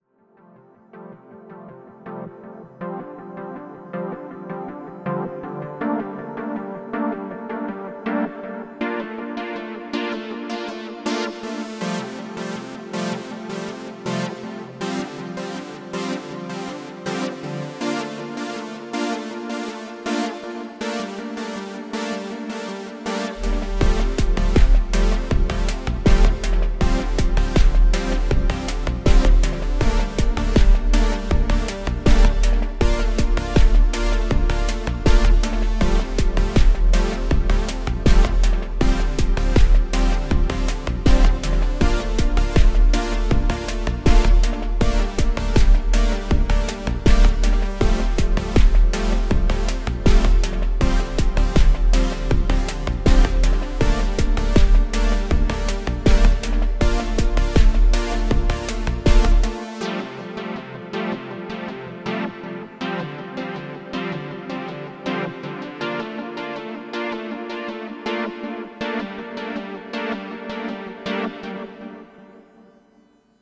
Exploring the "synthwave" chord progressions on the J-6. ...